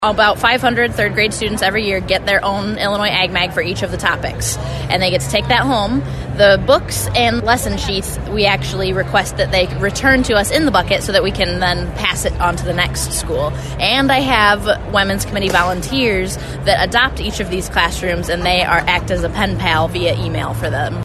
WCMY News